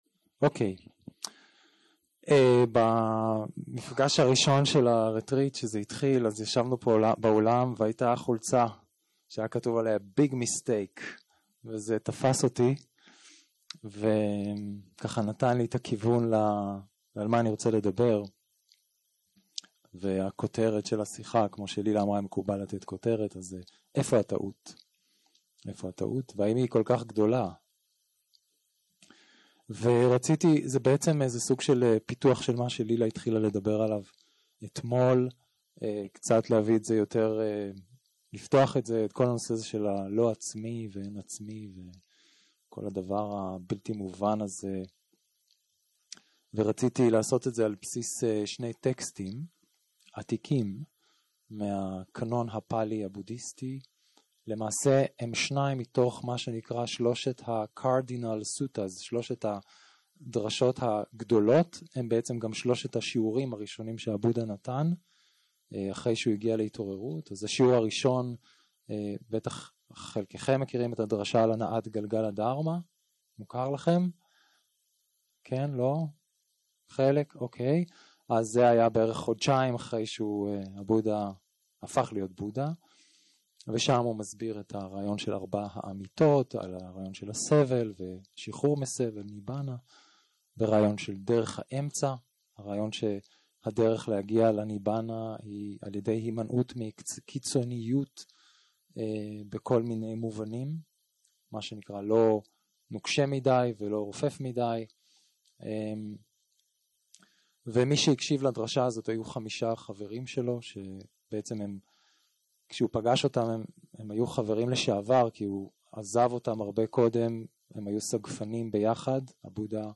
יום 3 - הקלטה 6 - ערב - שיחת דהרמה - איפה הטעות, והאם היא כל כך גדולה
יום 3 - הקלטה 6 - ערב - שיחת דהרמה - איפה הטעות, והאם היא כל כך גדולה 0:00 0:00 סוג ההקלטה: Dharma type: Dharma Talks שפת ההקלטה: Dharma talk language: Hebrew